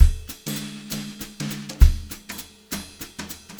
200JZBEAT4-L.wav